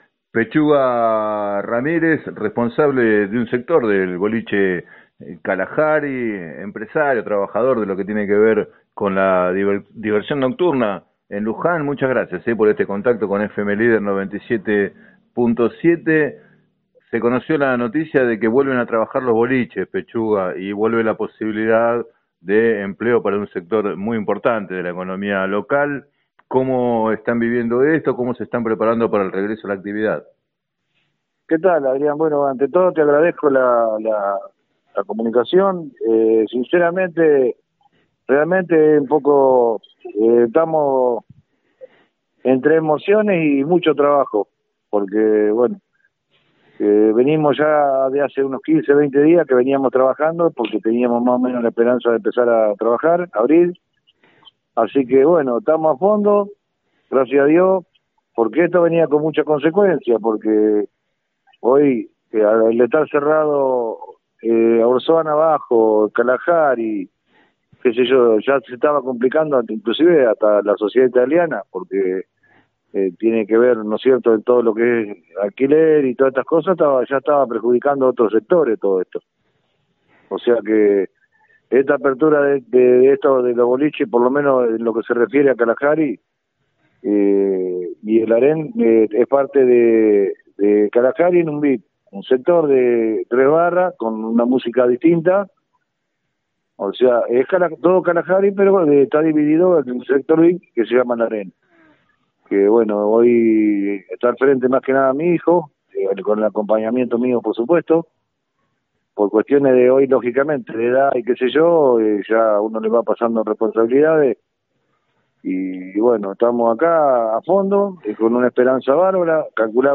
En declaraciones al programa “Planeta Terri” de FM Líder 97.7